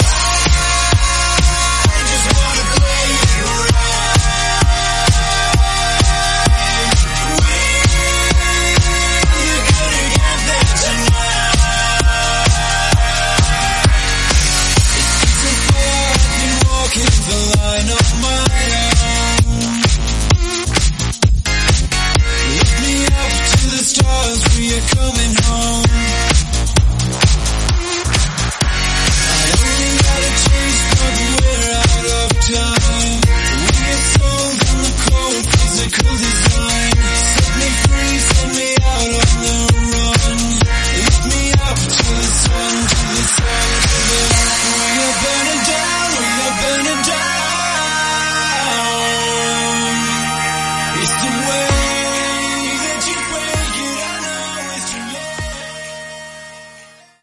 Genre: 90's
Clean BPM: 140 Ti